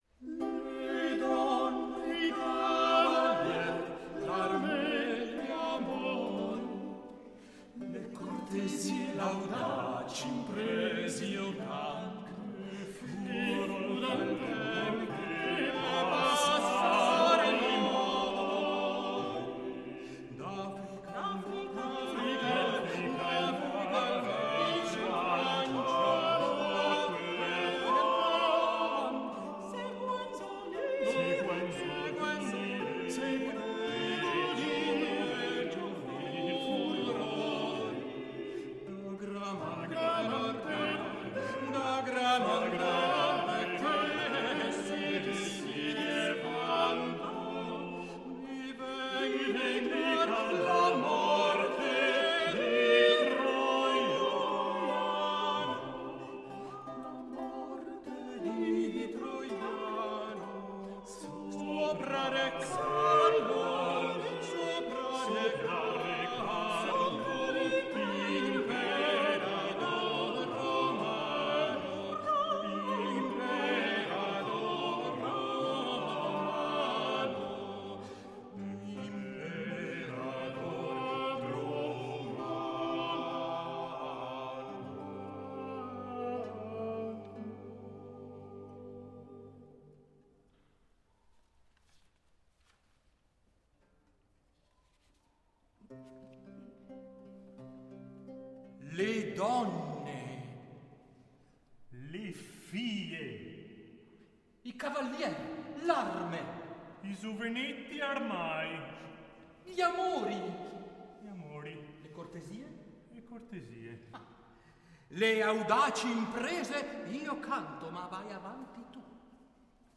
strambotti e frottole
madrigale. Ne è risultato un concerto/rappresentazione che ha incontrato l’entusiasmo del pubblico che ha gremito la chiesa romanica dei Santi Pietro e Paolo a Biasca nel contesto del 31mo Festival Cantar di Pietre.